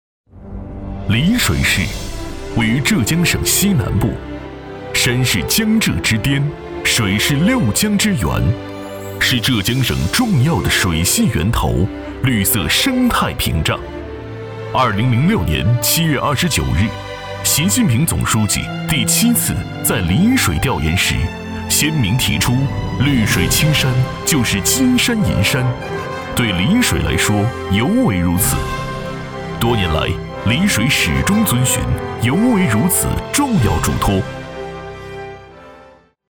城市宣传片男175号（丽水
年轻时尚 城市形象
年轻时尚，富有感情。大气激情男音，擅自专题汇报、宣传片、旁白等题材。作品：丽水宣传片。